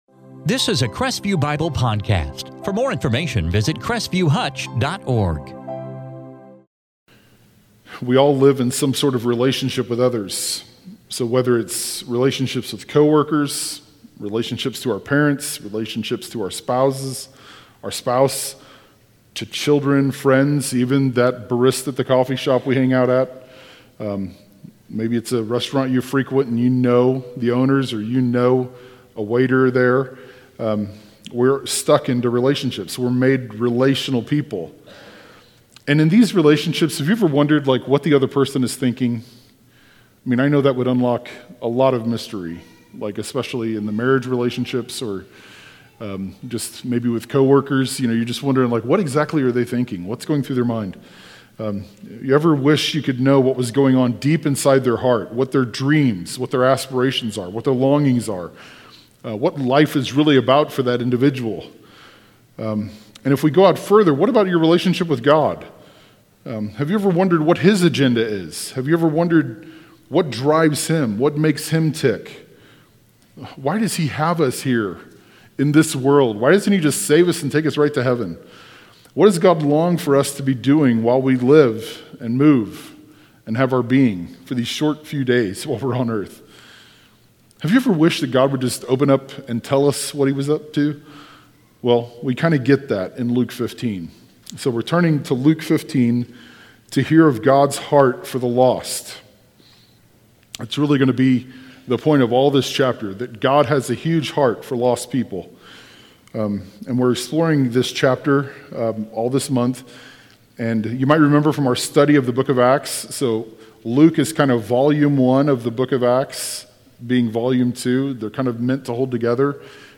In this sermon from Luke 15:1-7